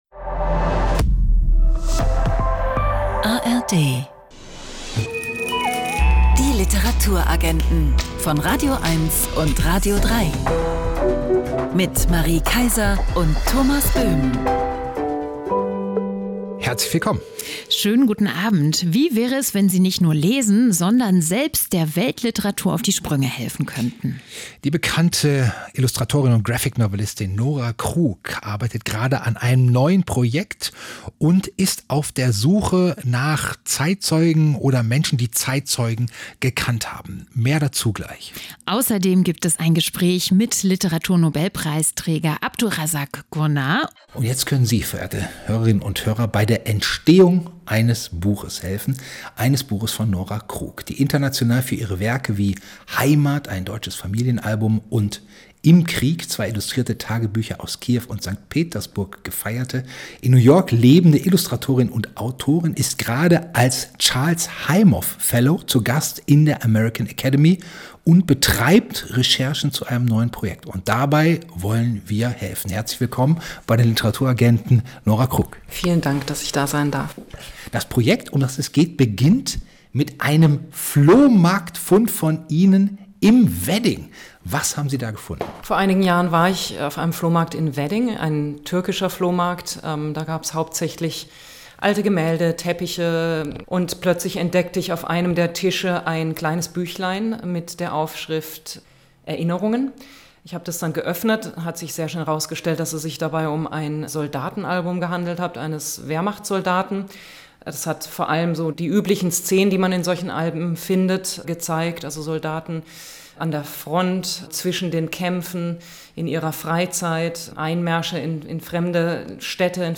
Außerdem in der Sendung: ein Gespräch mit Literaturnobelpreisträger Abdulrazak Gurnah über seinen neuen Roman „Diebstahl“, Berlin-Flaneur David Wagner zu einem Buch über den „Stuttgarter Platz“, und ein Interview zu den schönsten Museen in Brandenburg.